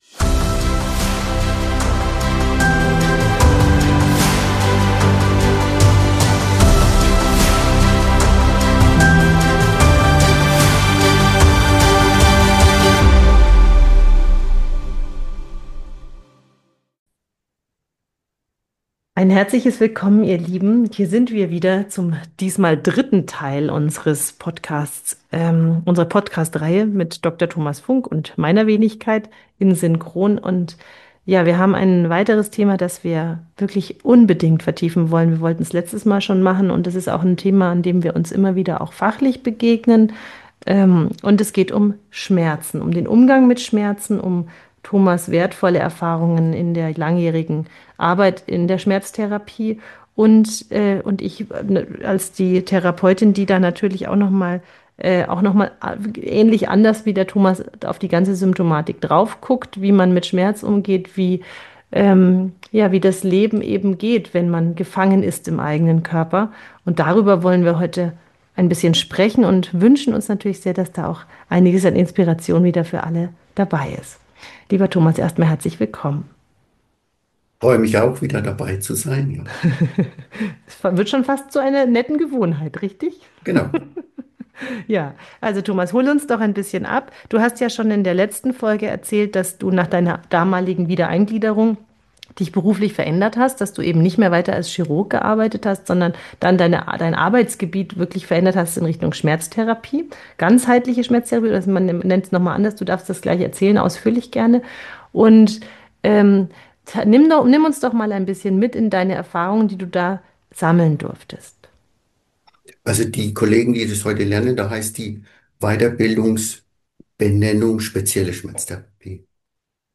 Ein Gespräch über Körper, Bewusstsein und den Mut, neue Wege im Umgang mit Leid zu finden.